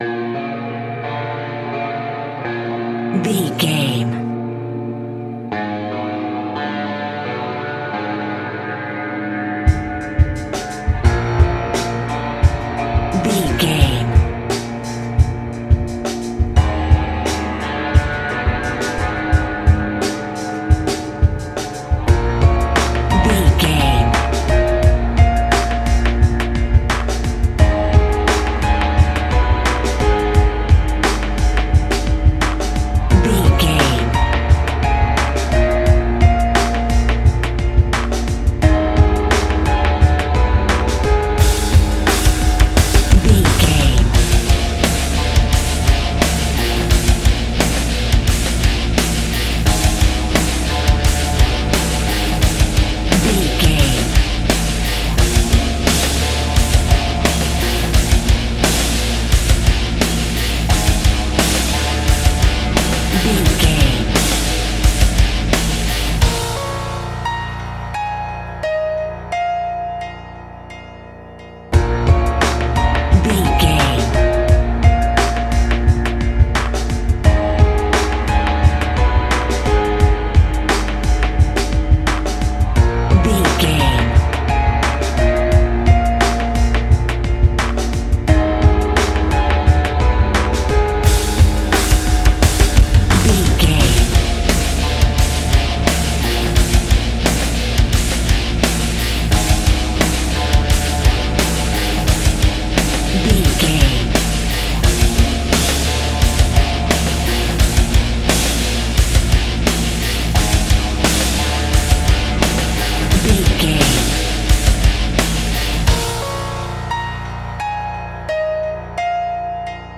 Aeolian/Minor
B♭
drums
electric piano
electric guitar
bass guitar
drum machine
pop rock
hard rock
lead guitar
aggressive
energetic
intense
powerful
nu metal
alternative metal